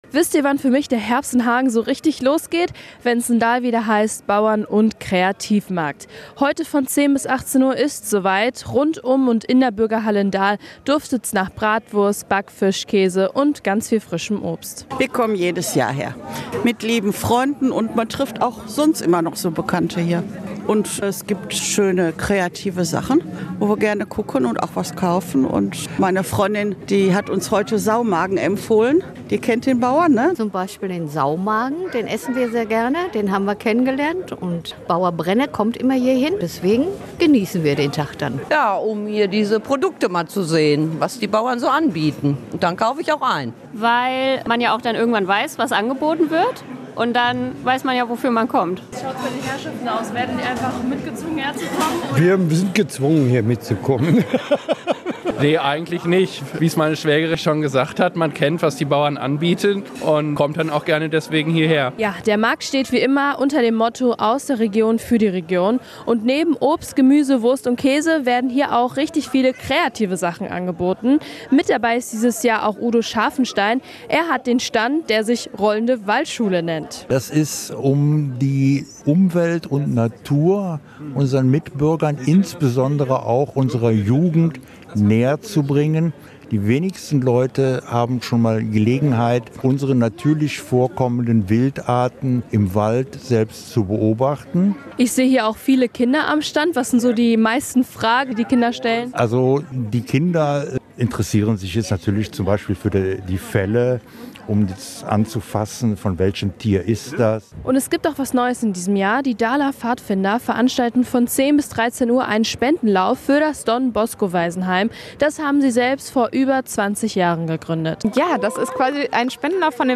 reportage-bauernmarkt-dahl.mp3